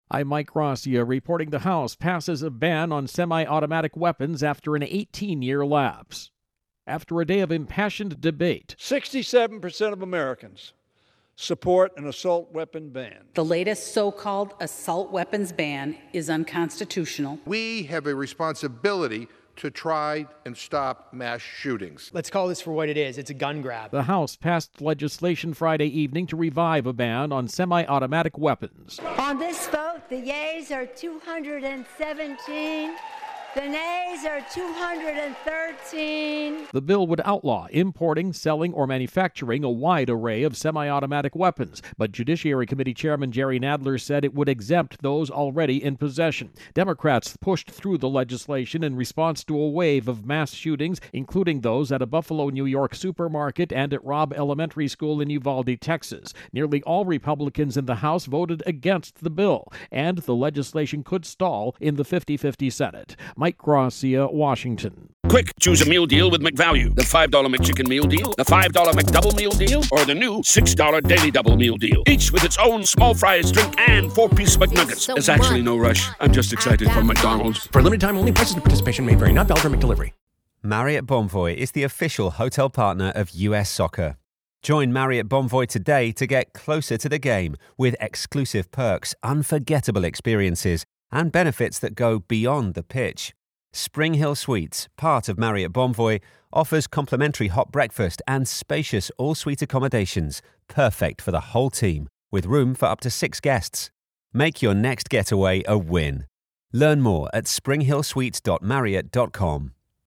AP correspondent
reports on Congress-Guns.